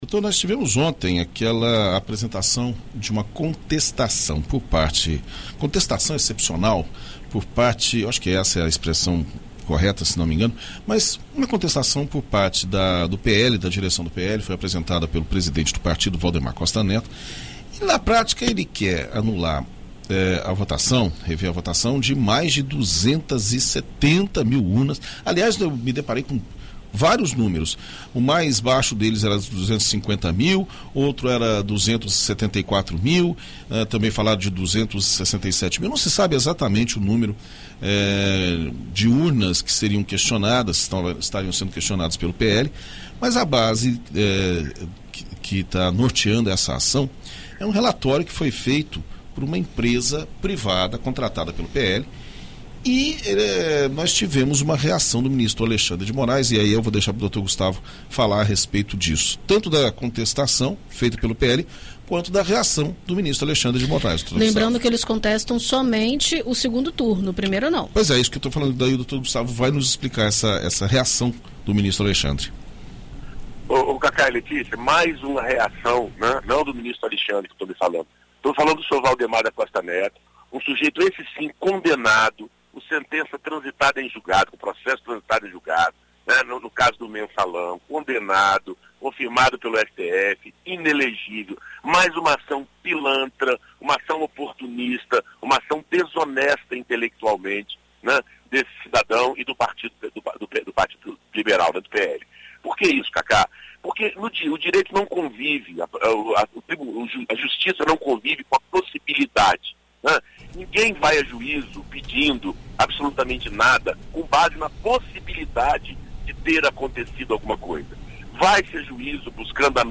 Na coluna Direito para Todos desta quarta-feira (23), na BandNews FM Espírito Santo